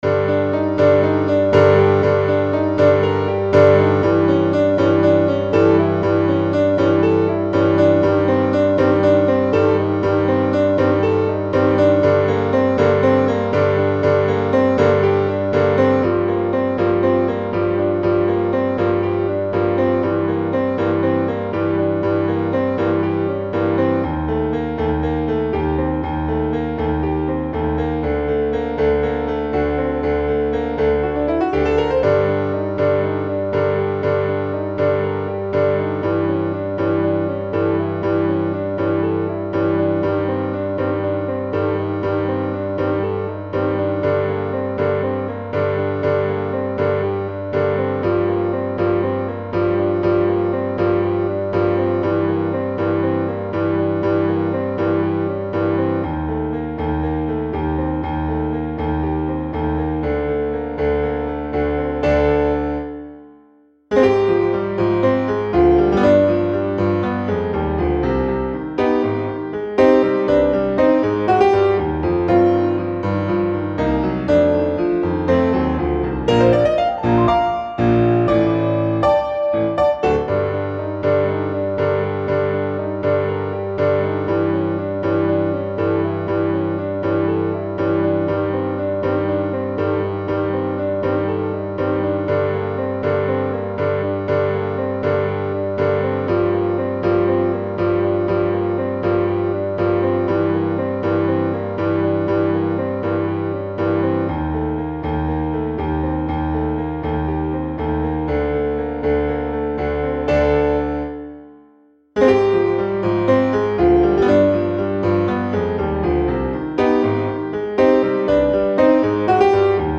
TROMBA SOLO • ACCOMPAGNAMENTO PIANO + BASE MP3
Piano 1 - Piano 2